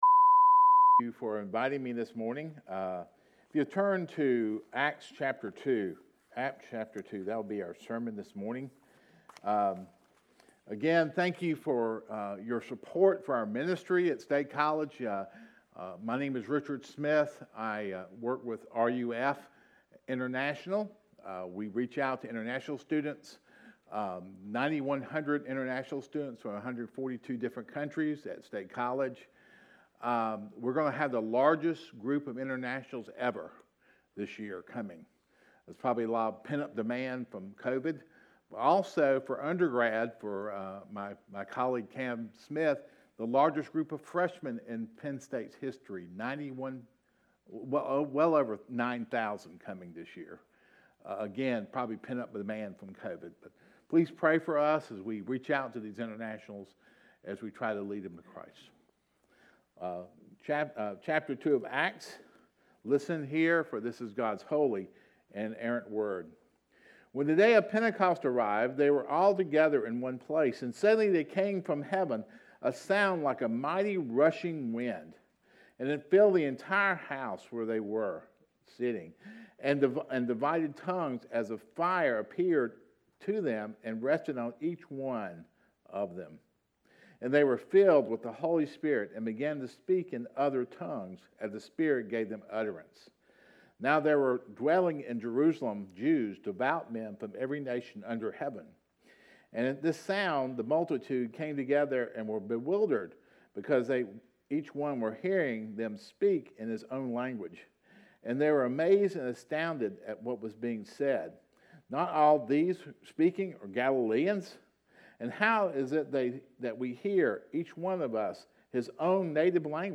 Sunday Worship – December 29 of 2024 – “The Genealogy of Hope”
8.7.22-sermon-audio.mp3